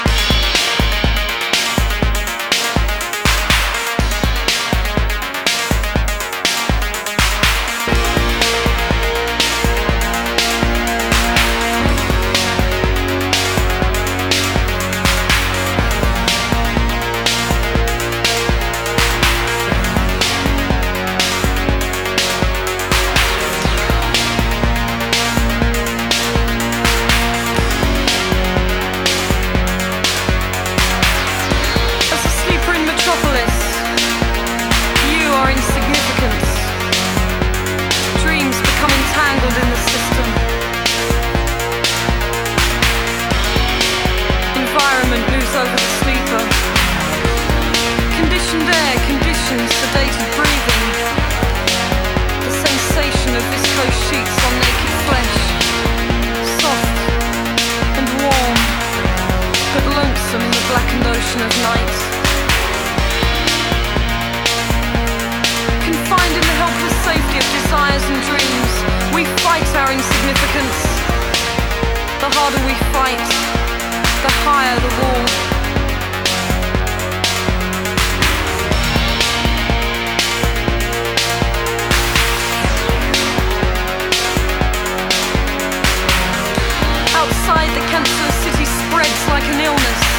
remezcla extendida